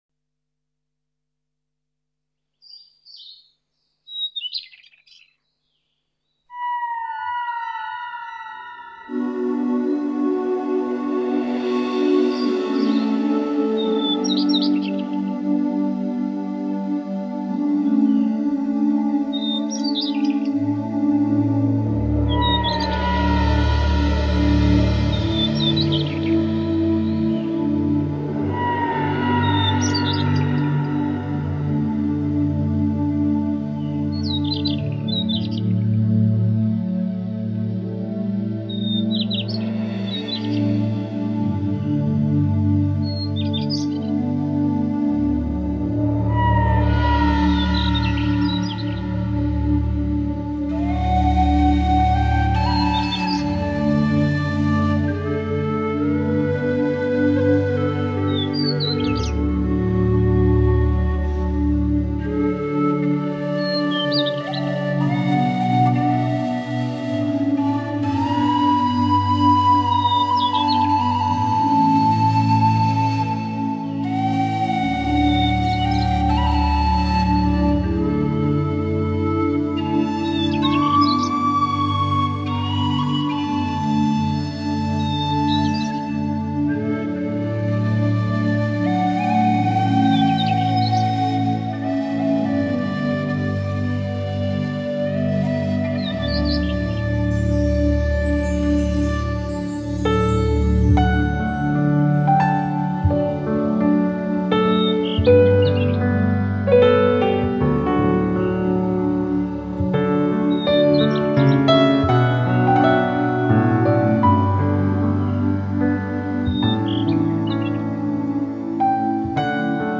10多种鸟、歌手跨越族群联手演出多种轻柔乐器和鸣鸟啼流水、情人私语，比翼翱翔，解码它们私密快乐中的共同语言。